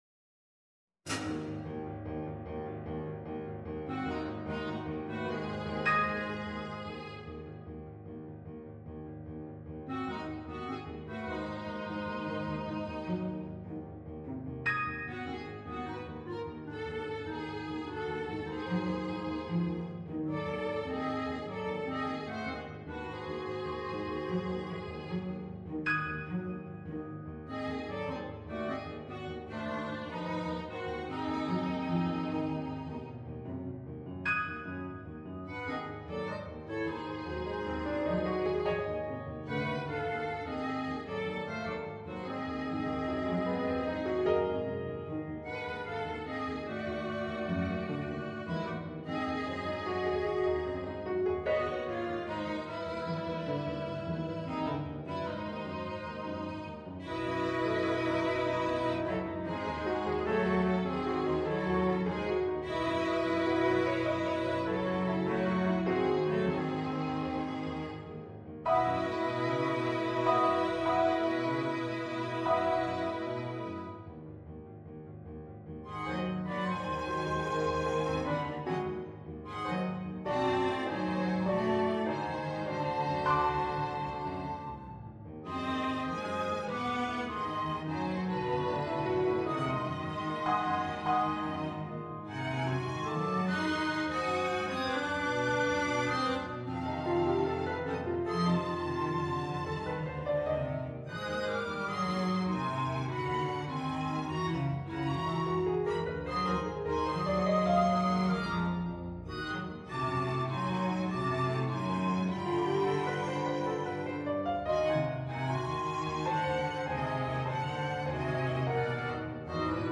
MIDI recording
Having heard my mixed quintet Hovenweep played many times, I decided it was time to write a new "Pierrot ensemble" piece: flute, clarinet, violin, cello, piano. For Running Quietly Amok I returned to the style of classic "grid-pulse postminimalism," if you know the term from my writings.
I include a MIDI recording, because in this case I think it represents the music rather well.